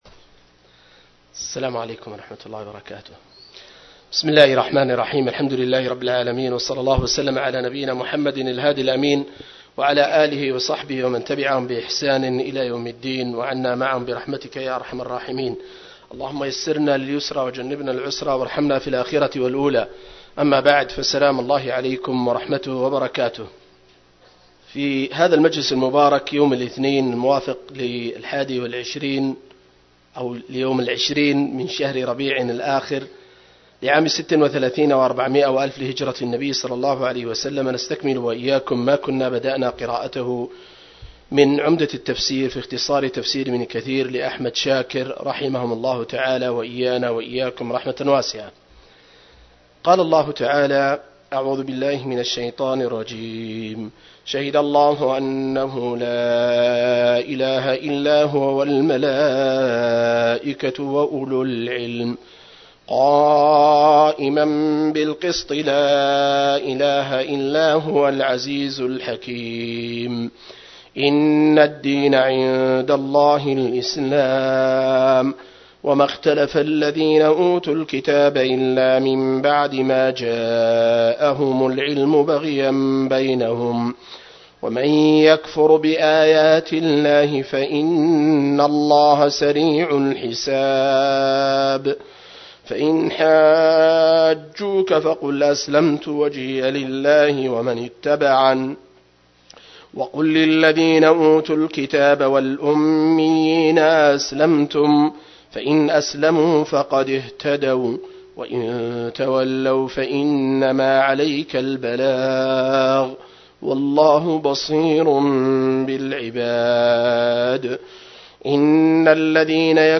063- عمدة التفسير عن الحافظ ابن كثير رحمه الله للعلامة أحمد شاكر رحمه الله – قراءة وتعليق –